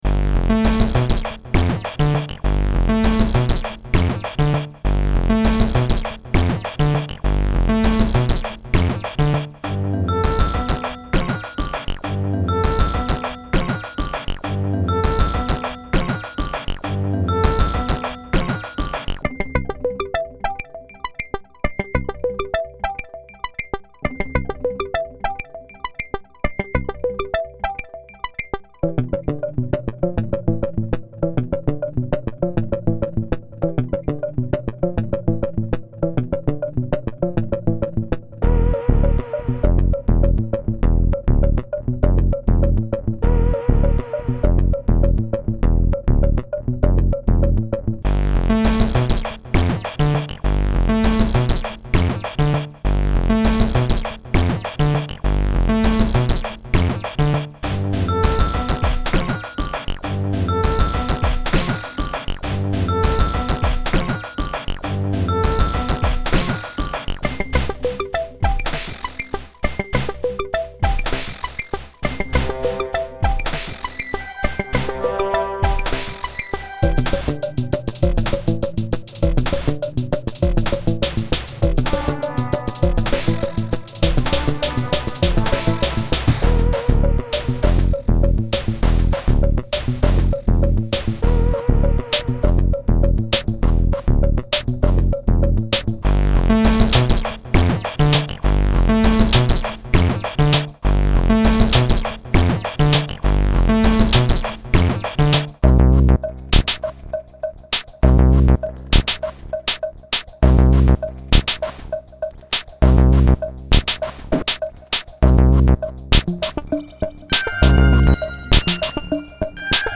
My remixed version was done with Cubase, virtual reverb and a drum-sampler with my Sidstation kit.
This explains the slightly halting, lo-fi-but-not-to-the-point-of-being-raspy quality of the sounds.
Am resisting the urge to bump the volume to "CD level" as I think that will make it too harsh.